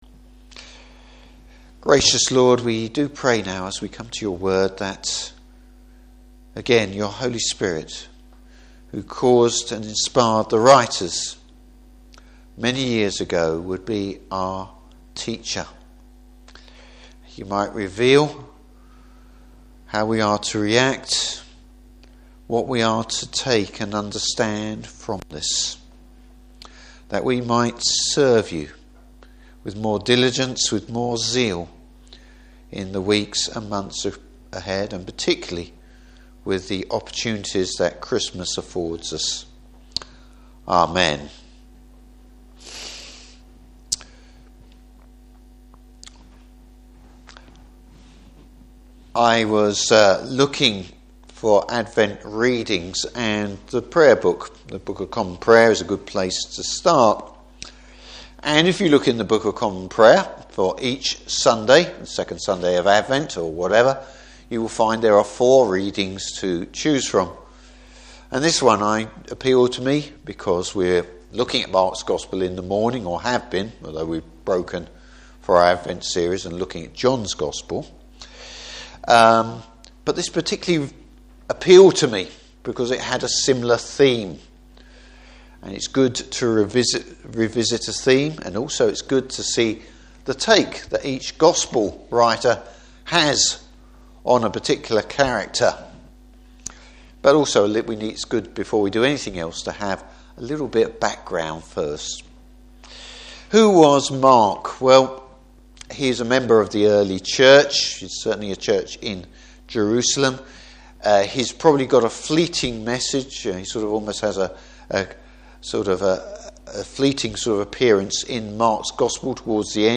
Service Type: Evening Service Mark’s dramatic start to his Gospel.